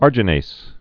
(ärjə-nās, -nāz)